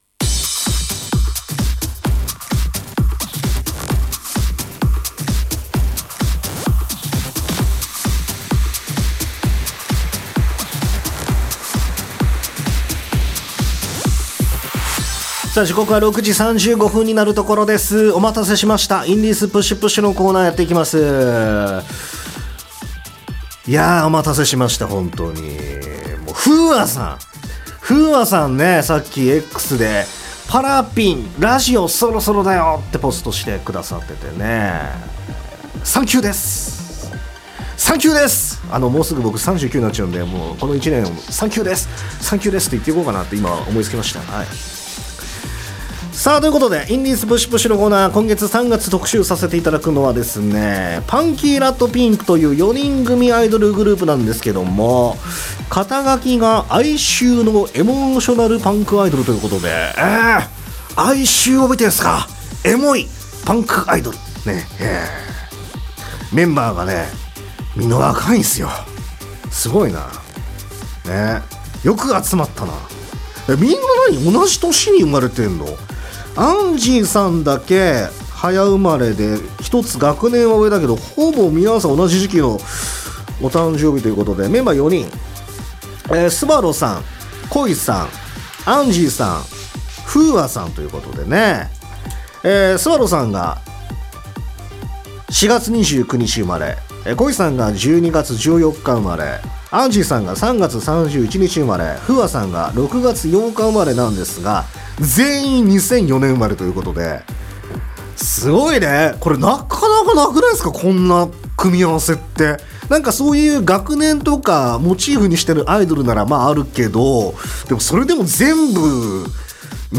”哀愁のエモーショナルパンクアイドル”